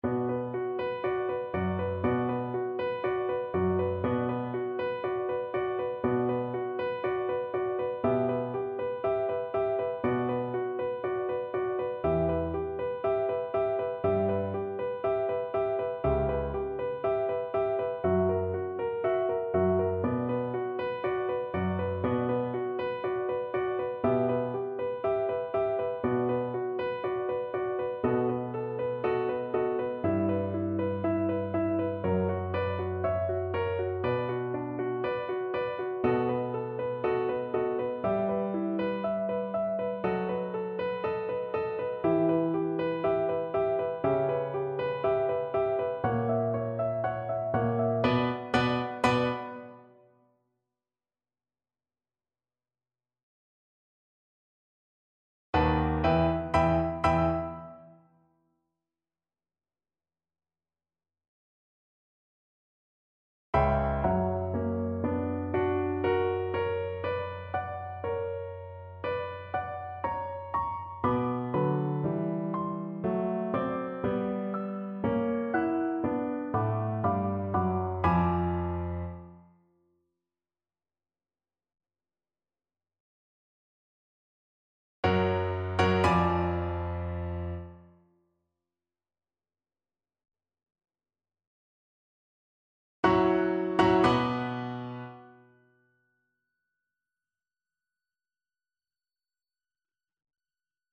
2/2 (View more 2/2 Music)
Allegro, molto appassionato (View more music marked Allegro)
Classical (View more Classical Double Bass Music)